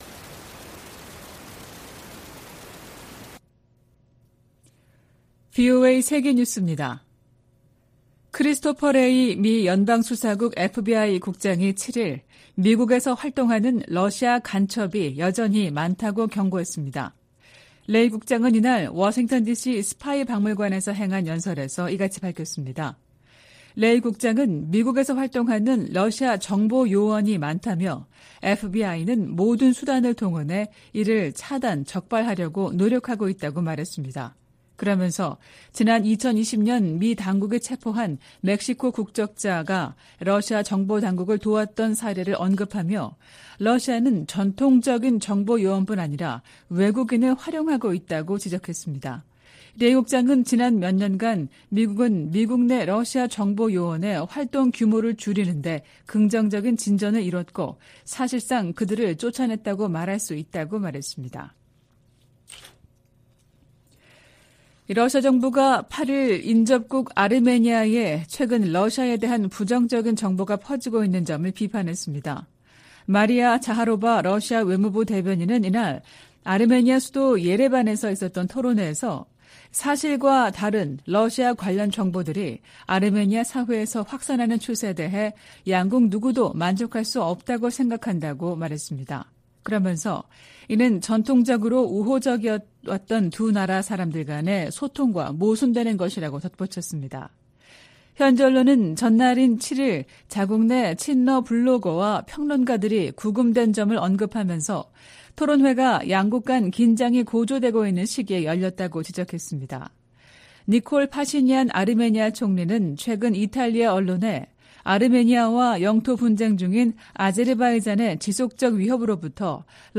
VOA 한국어 '출발 뉴스 쇼', 2023년 9월 9일 방송입니다. 북한이 수중에서 핵 공격이 가능한 첫 전술 핵공격 잠수함인 '김군옥 영웅함'을 건조했다고 밝혔습니다. 인도네시아에서 열린 동아시아정상회의(EAS)에 참석한 카멀라 해리스 미국 부통령이 북한의 위협적 행동을 강력히 규탄했습니다. 러시아와 무기 거래를 하려는 북한은 '매우 위험한 게임'을 하는 것이라고 미국 상원 외교위원장이 지적했습니다.